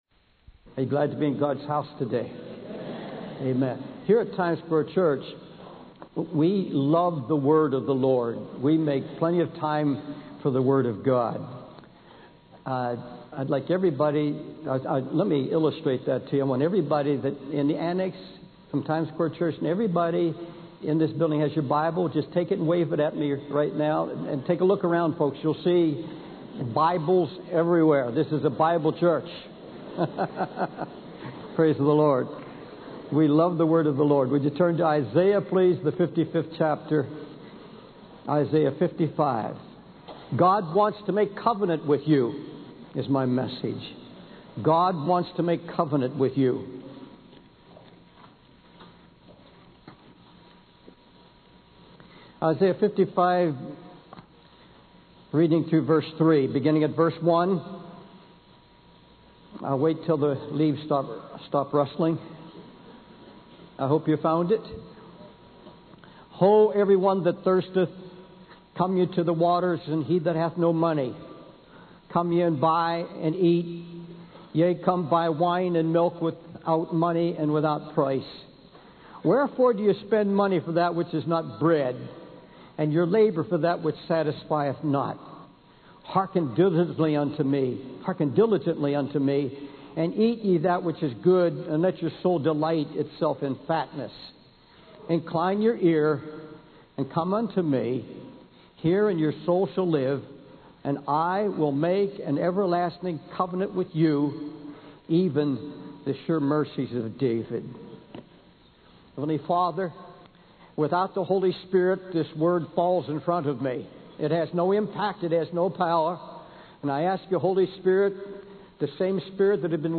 In this sermon, the preacher emphasizes the difference between the Old Covenant and the New Covenant in serving God.